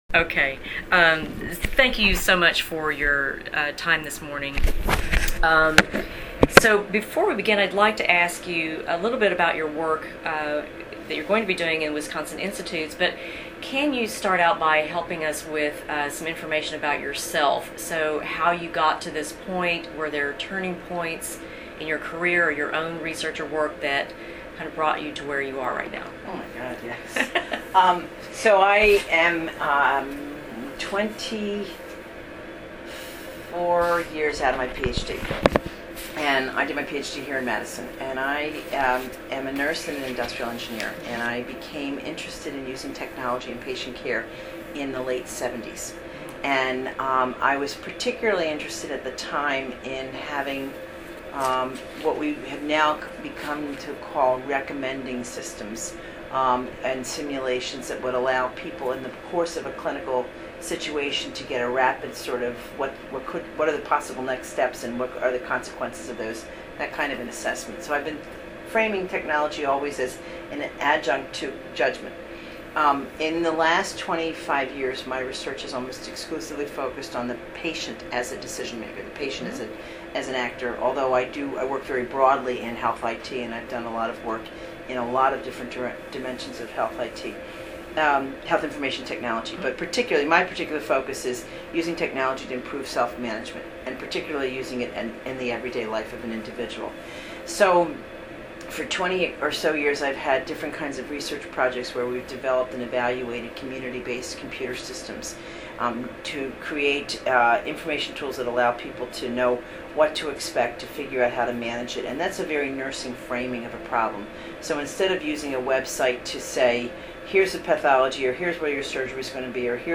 Recording, oral